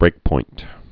(brākpoint)